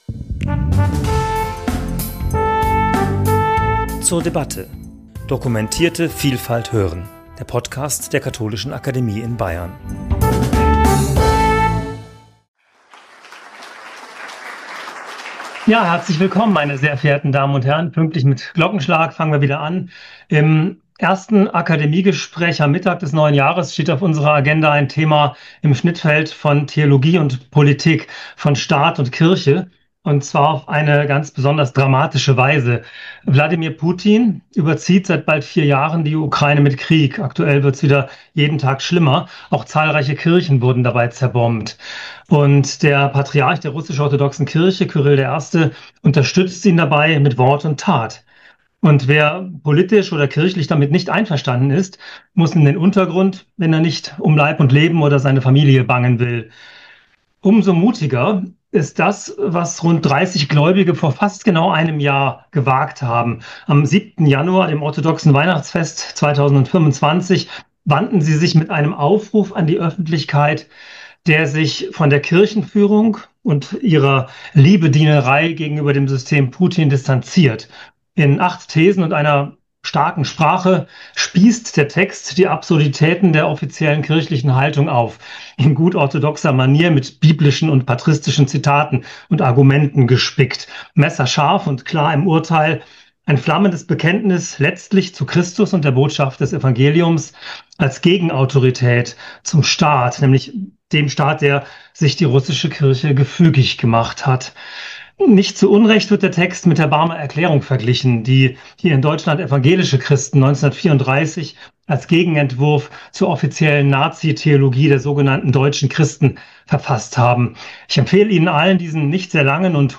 Gespräch zum Thema 'Ein russischer Aufruf gegen den Krieg in der Ukraine' ~ zur debatte Podcast